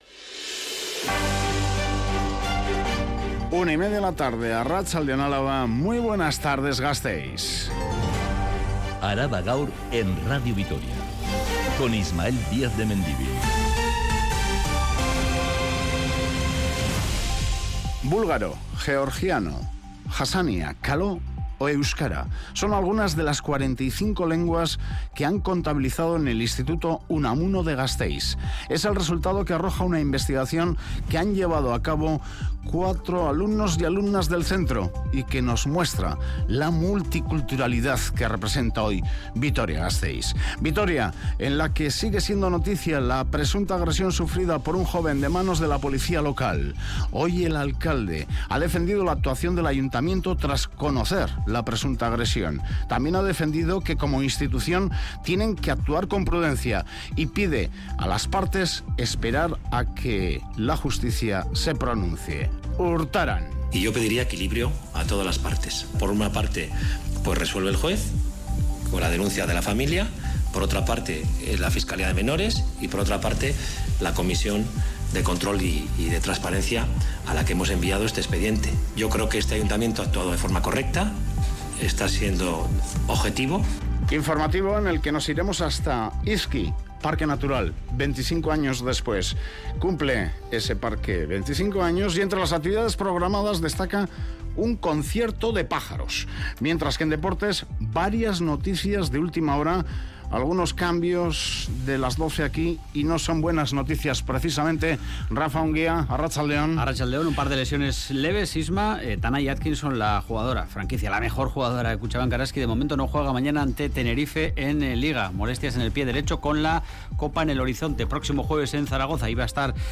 Radio Vitoria ARABA_GAUR_13H Araba Gaur (Mediodía) (24/03/2023) Publicado: 24/03/2023 16:16 (UTC+1) Última actualización: 24/03/2023 16:16 (UTC+1) Toda la información de Álava y del mundo. Este informativo que dedica especial atención a los temas más candentes de la actualidad en el territorio de Álava, detalla todos los acontecimientos que han sido noticia a lo largo de la mañana.